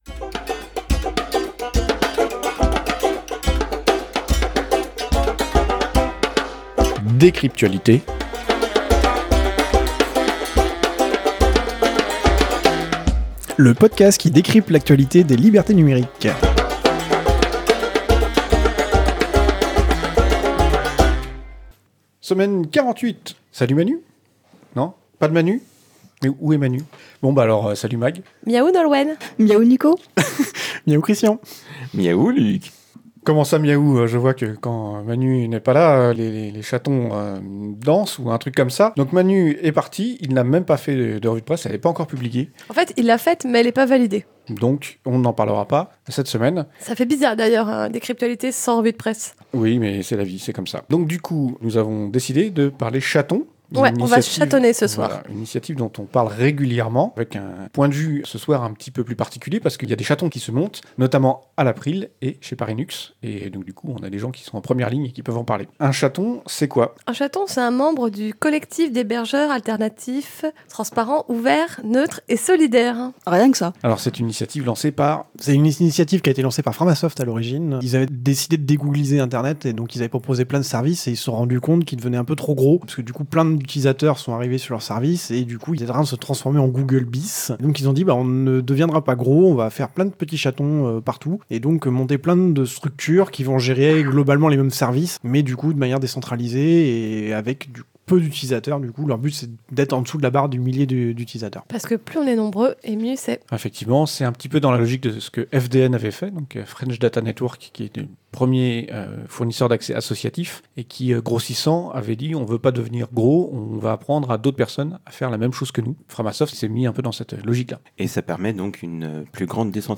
Studio d'enregistrement